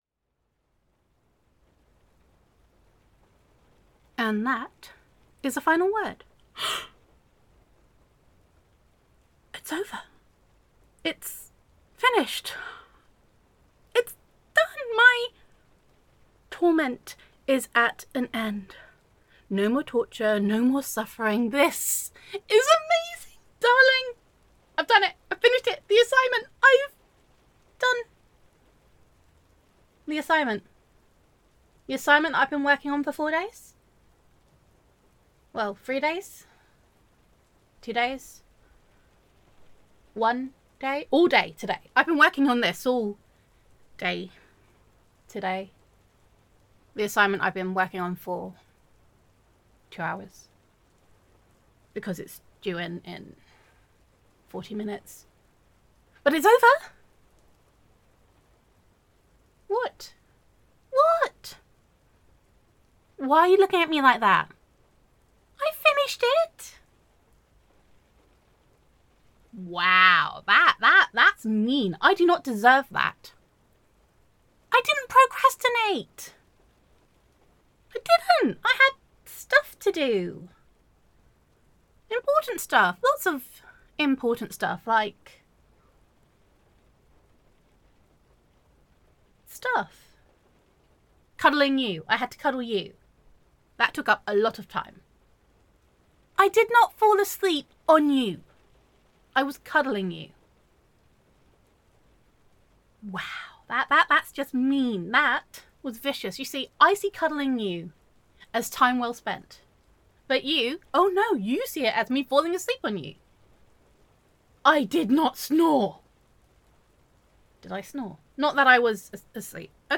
[F4A]